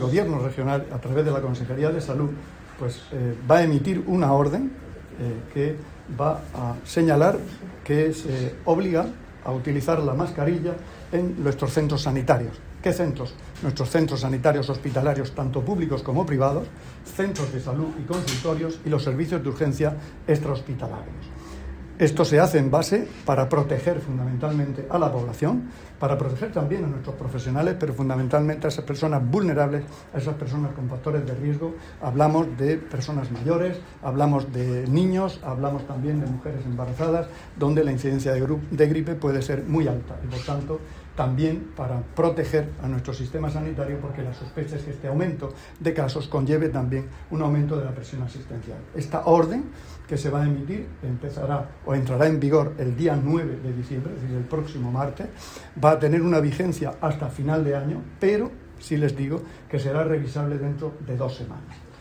Declaraciones del consejero de Salud, Juan José Pedreño, sobre la orden que establecerá la obligatoriedad del uso de mascarillas en centros sanitarios.